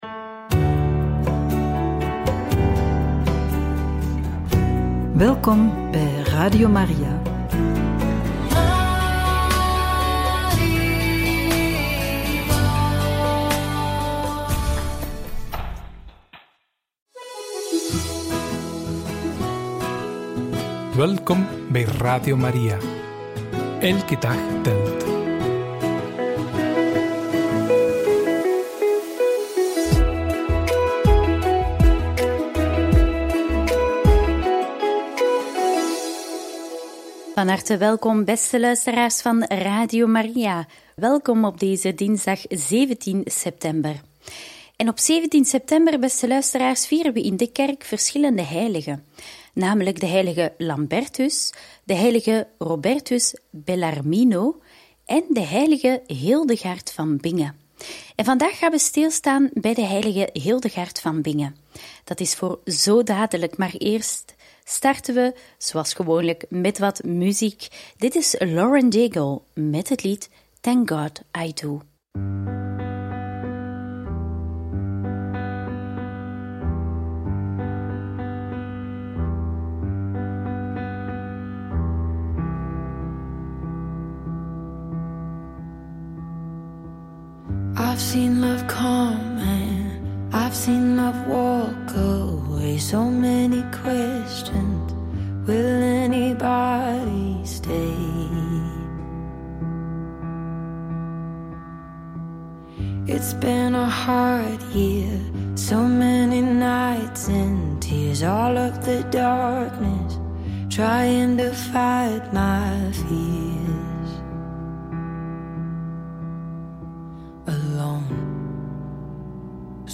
Wie was de heilige Hildegard van Bingen? In gesprek